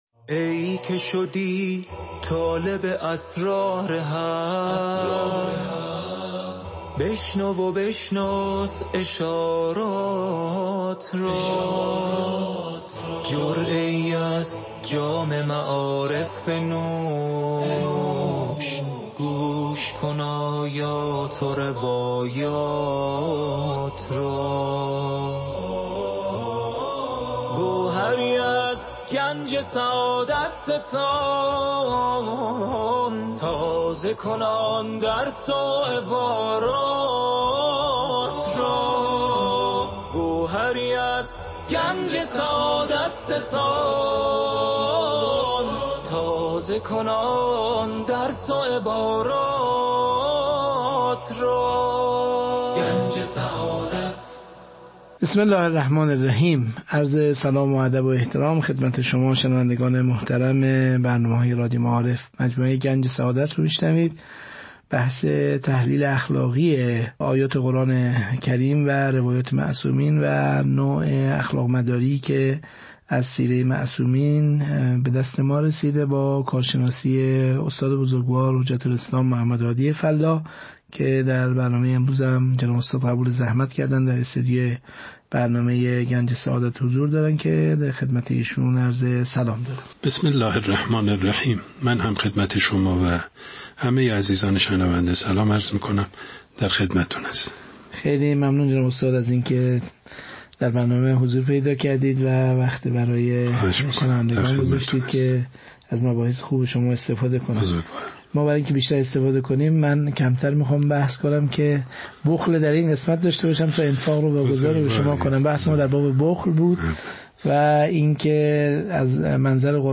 درس اخلاق | چرا انفاق برای مدیران یک وظیفه ضروری است؟